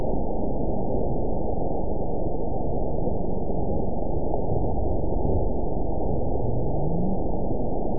event 912475 date 03/27/22 time 10:17:32 GMT (3 years, 1 month ago) score 9.62 location TSS-AB05 detected by nrw target species NRW annotations +NRW Spectrogram: Frequency (kHz) vs. Time (s) audio not available .wav